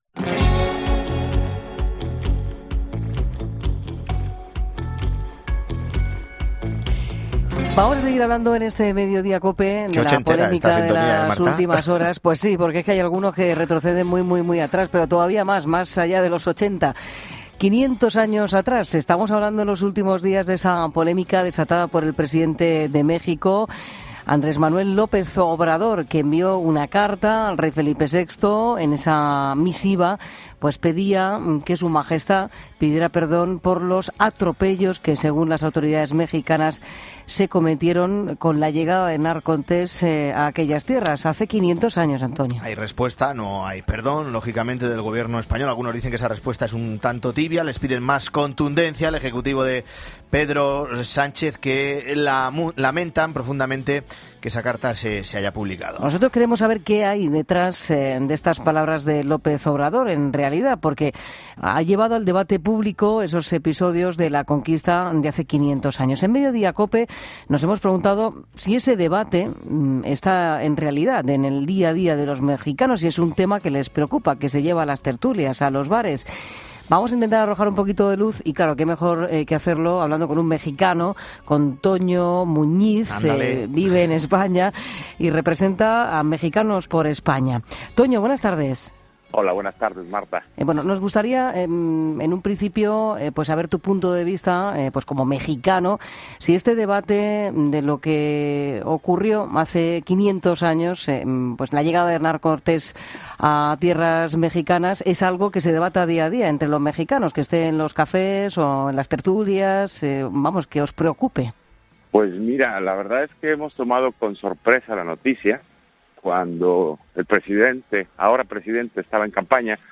Un periodista mexicano: “En el día a día de México la gente no habla de la conquista”